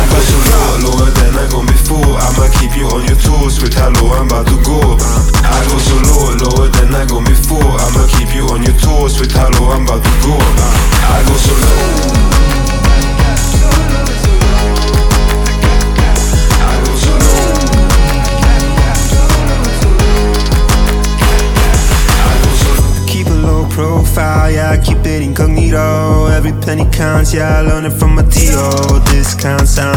Жанр: Иностранный рэп и хип-хоп / R&b / Соул / Рэп и хип-хоп